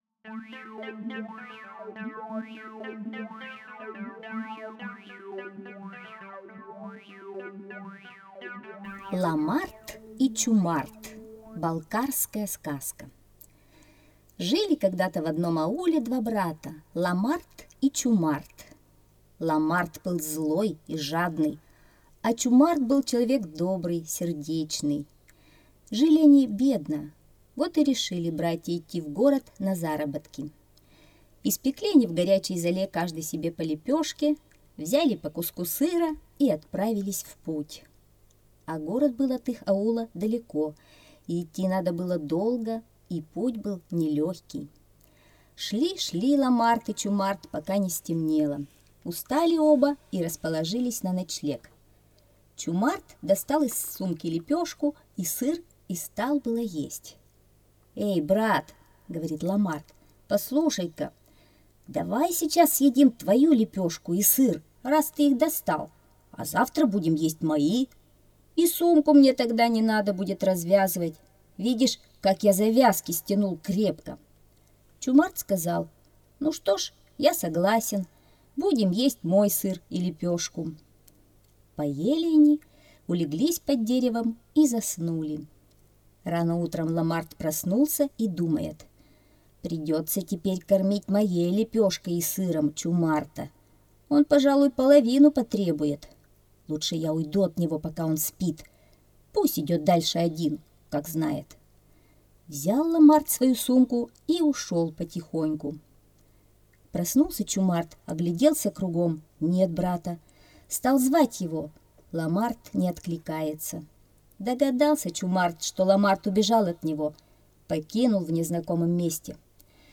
Ламарт и Чумарт - балкарская аудиосказка. Жили когда-то в одном ауле два брата. Жили они бедно, вот и решили пойти в город на заработки...